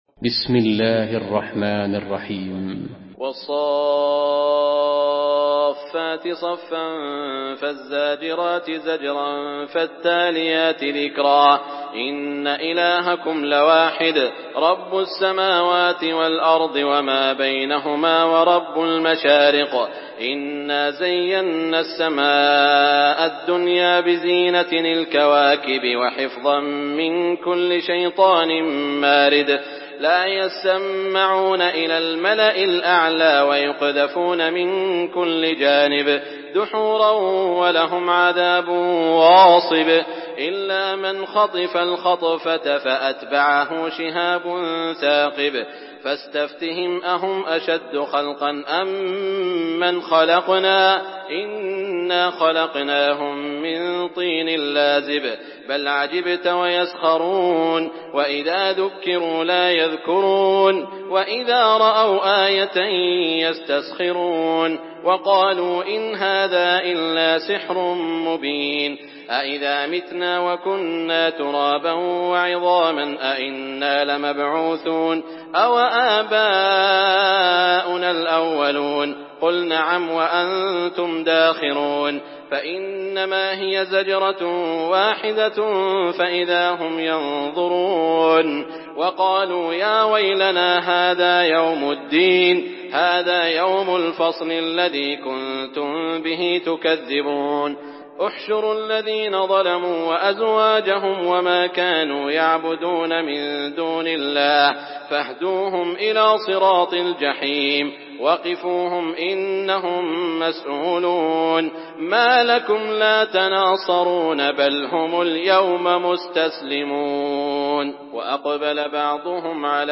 Surah আস-সাফ্‌ফাত MP3 in the Voice of Saud Al Shuraim in Hafs Narration
Murattal Hafs An Asim